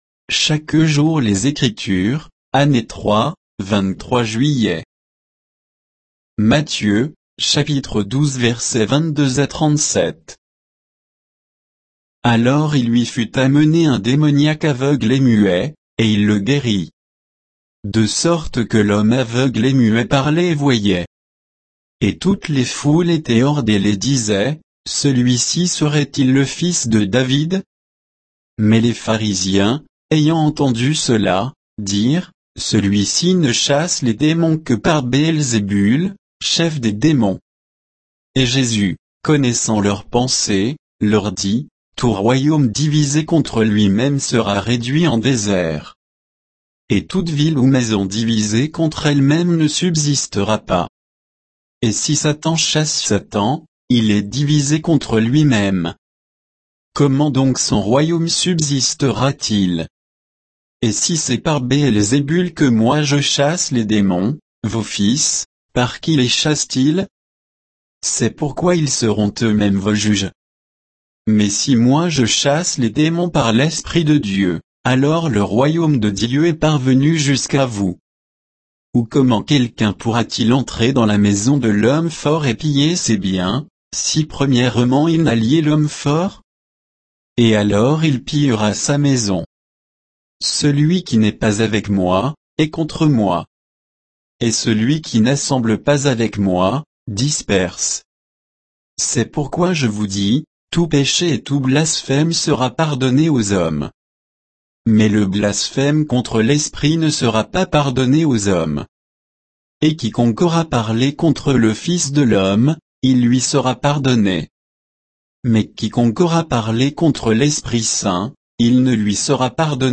Méditation quoditienne de Chaque jour les Écritures sur Matthieu 12, 22 à 37